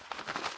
x_enchanting_scroll.5.ogg